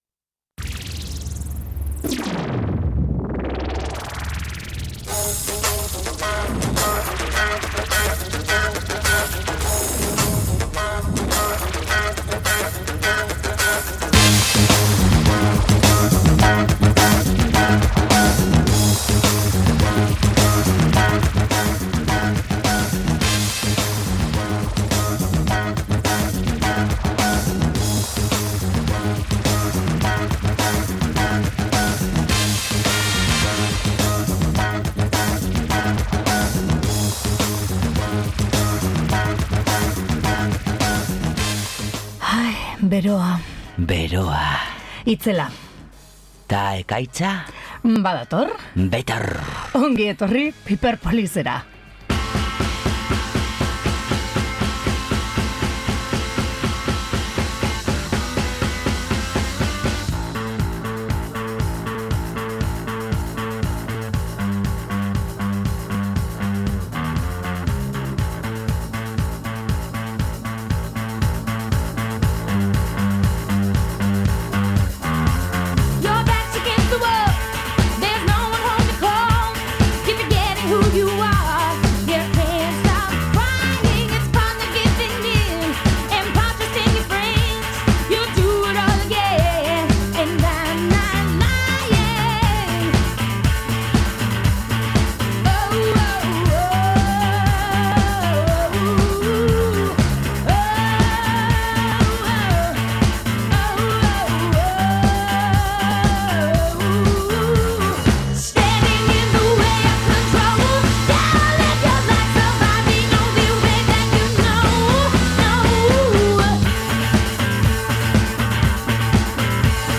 Erritmoz beterik etorri gara aste honetako azken piperpolisera. Udan euskal herrian izango diren jaialdi batzuk izan ditugu hizpide. Kurtsoan apaletan pilatutako disko berri eta gogokoak entzun ditugu.